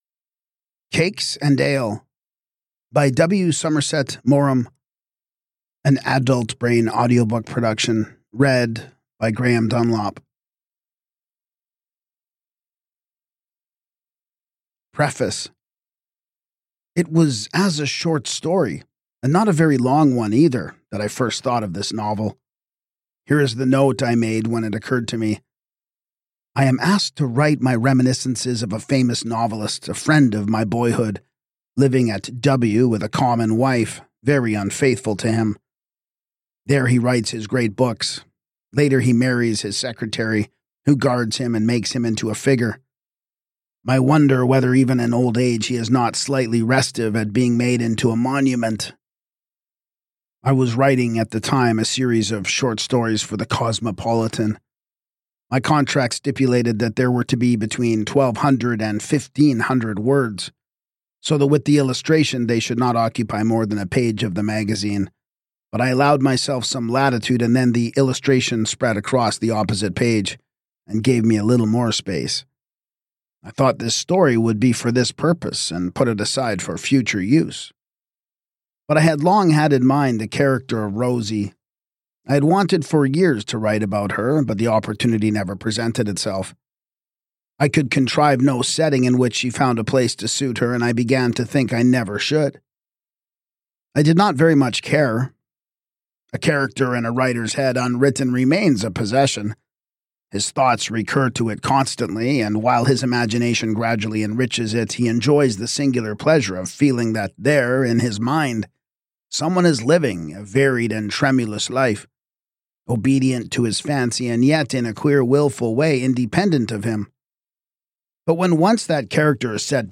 This complete audiobook is ideal for: